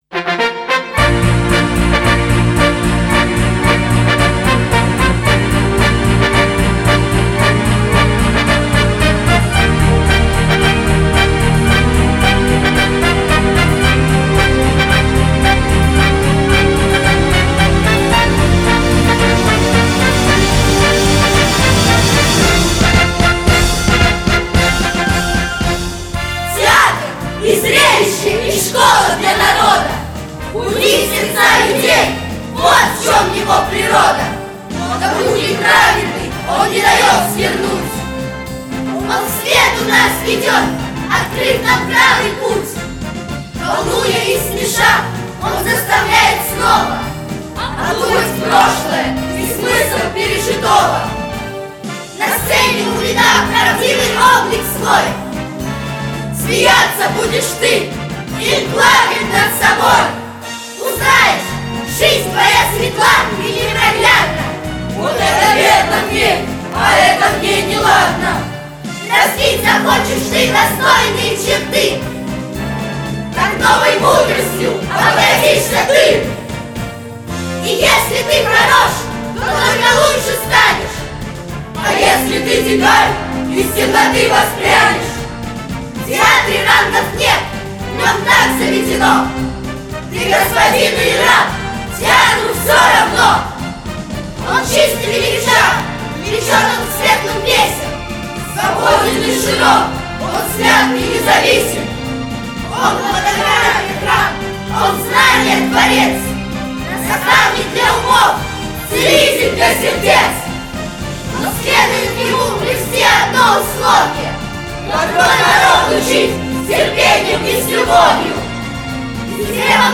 запись нашего речевого хора – Тукай. Театр